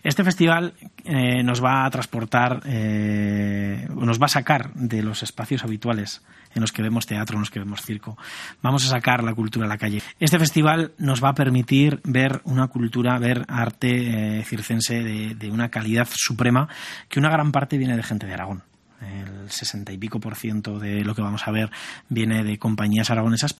Jesús Morales, alcalde de Quinto, explica los detalles del primer festival 'Circo se escribe sin H'.